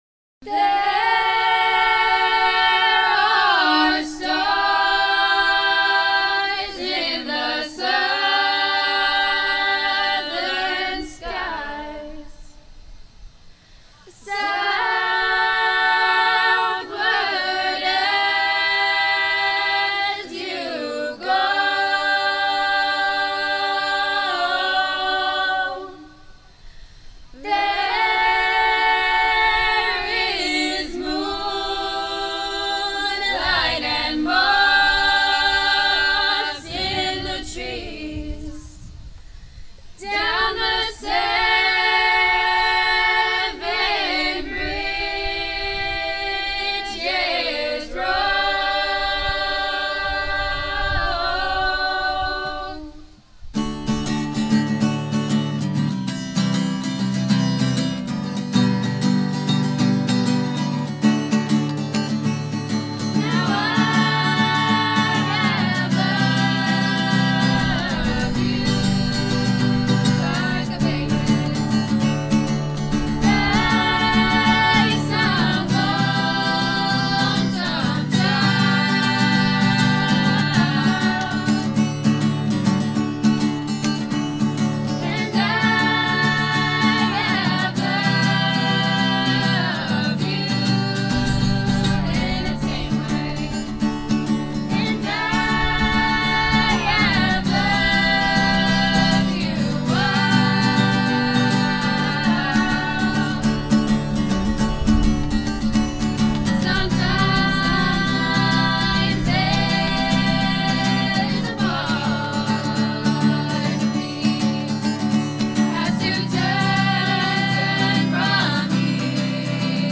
North Stonington Chorus: High-quality renders from the SoundCraft Ui24R.
Recorded with only two SM58s and lots of wind.
This event was recorded at the North Stonington Middle School-High School grounds in North Stonington, CT. using an Android Galaxy 7 Edge and a SoundCraft Ui24R on September 22nd, 12:00PM-4:00PM.
10-NSHS_Chorus_SevenBridges_HQ.mp3